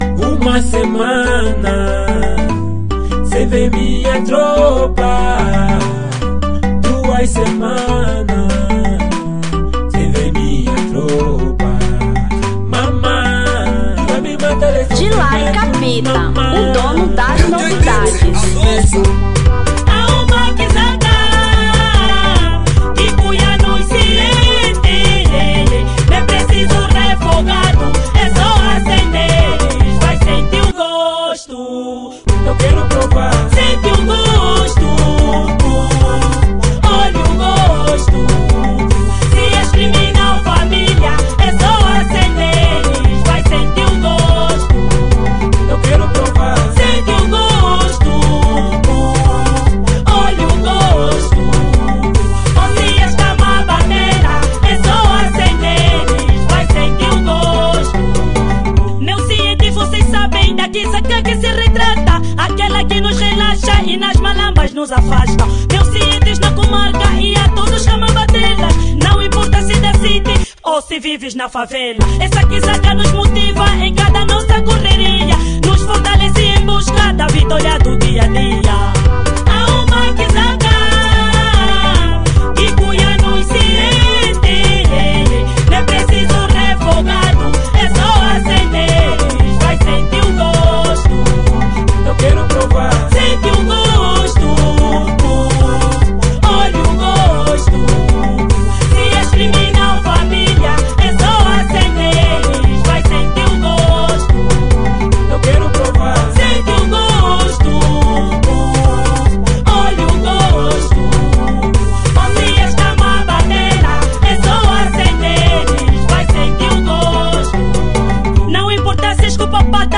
Kuduro 2018